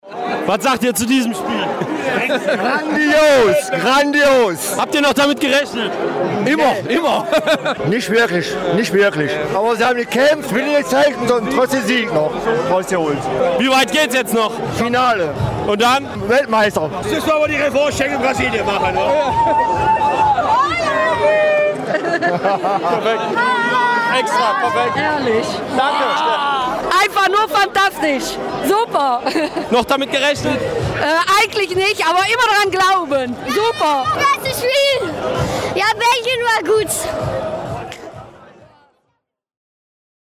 Der Name des Torschützen ging im Schlusspfiff-Jubel unter, es war Nacer Chadli, der die Roten Teufel und ihre Fans erlöste! Überragend, fanden auch diese Eupener: